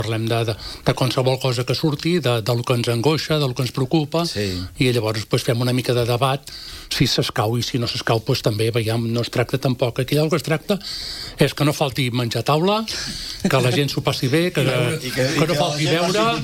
En declaracions a Ràdio Calella TV, relata que les converses poden anar per molts camins i que el més important és el bon ambient: